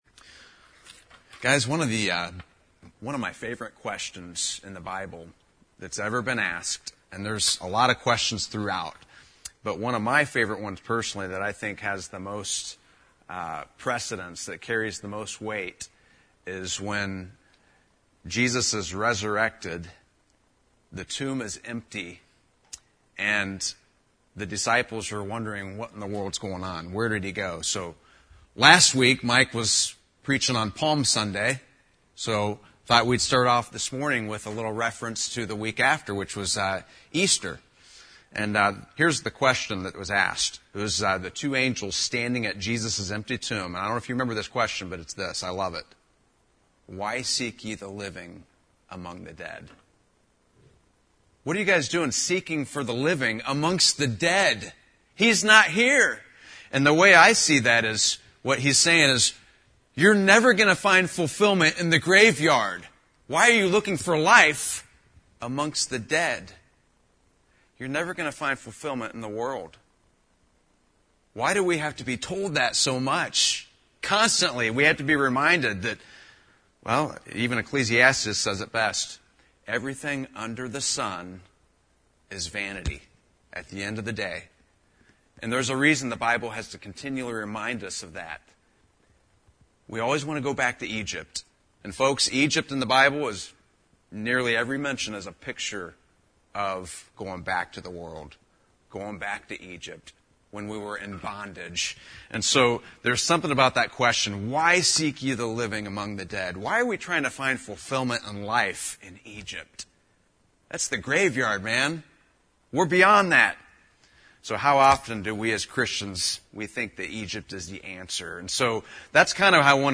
Sermons | Maple City Baptist Church